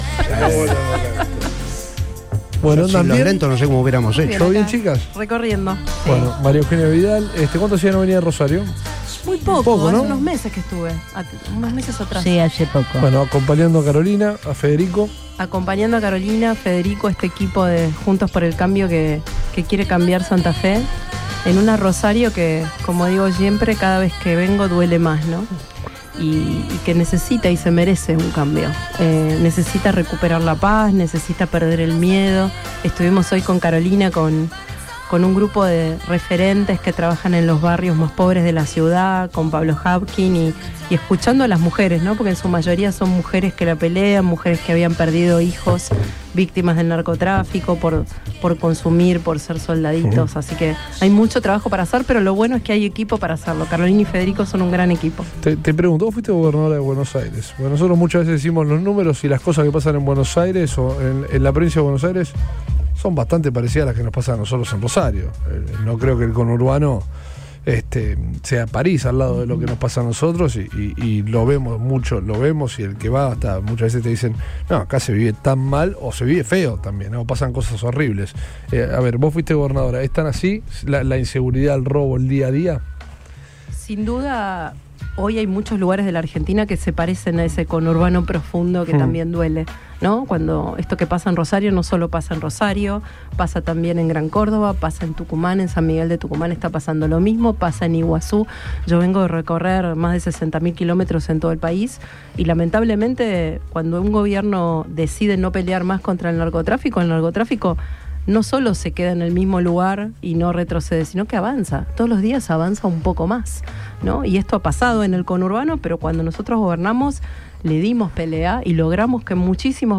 María Eugenia Vidal, ex gobernadora de la provincia de Buenos Aires y referente de Juntos por el Cambio pasó por los micrófonos de “Todo Pasa” acompañando a…